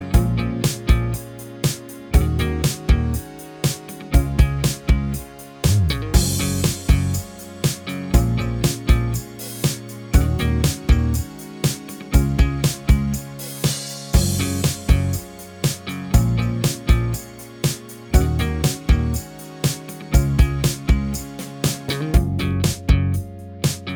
Minus All Guitars Pop (2000s) 3:52 Buy £1.50